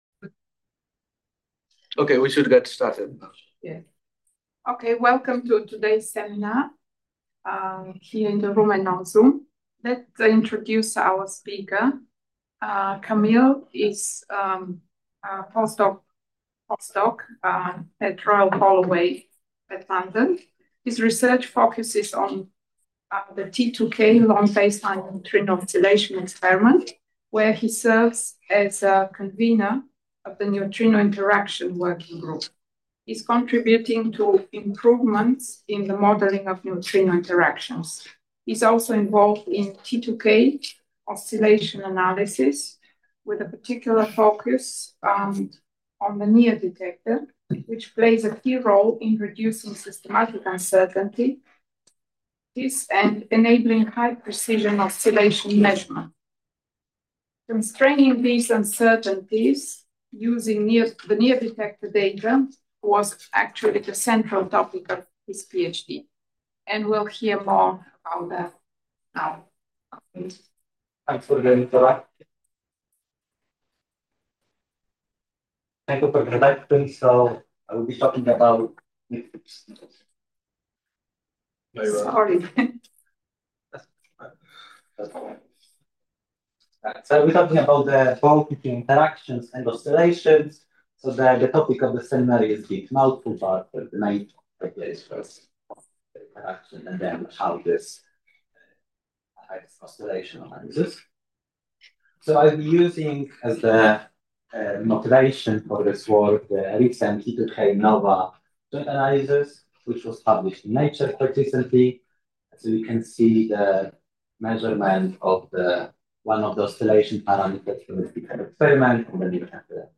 Achieving this level of precision requires careful control of systematic uncertainties, particularly those associated with the modelling of neutrino–nucleus interactions. This seminar will present an overview of neutrino interaction...